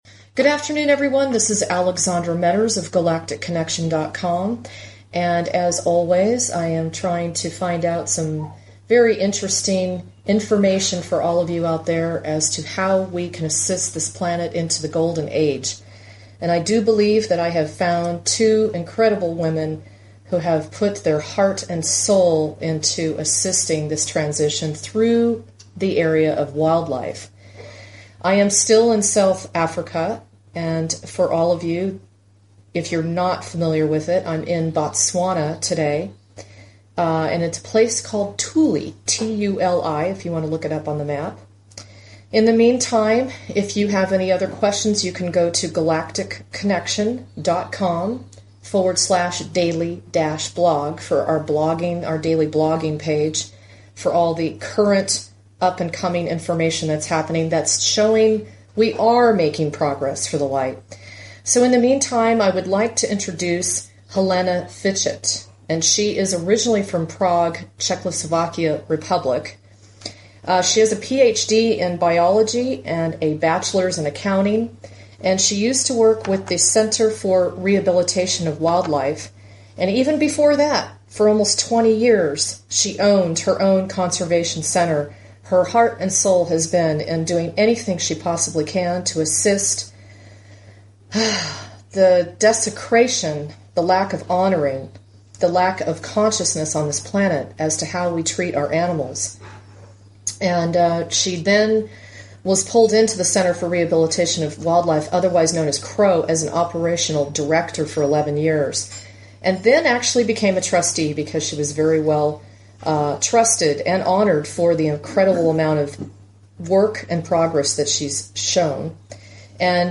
In this interview, you will not only here what it is like to run a game reserve, the goings-on of the local Botswanans and ranchers, but you will hear her rescued meerkat screeching in the background as I attempted to pick him up in the middle of the interview.